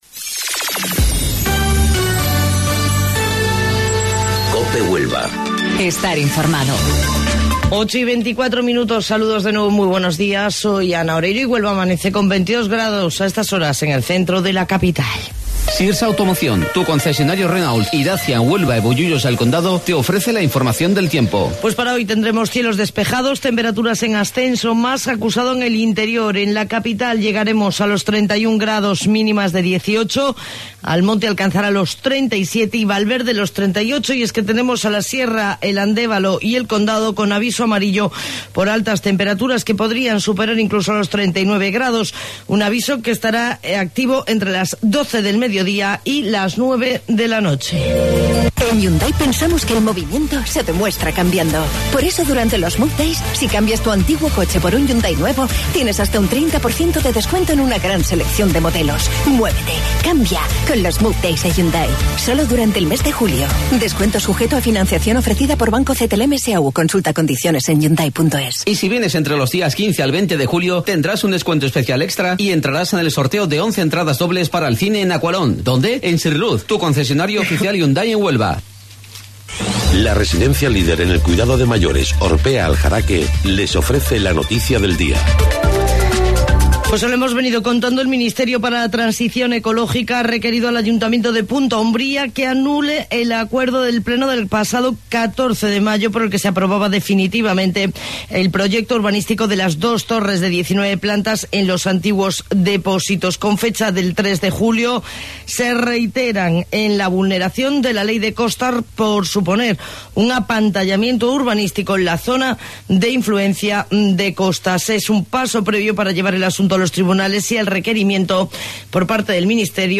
AUDIO: Informativo Local 08:25 del 11 de Julio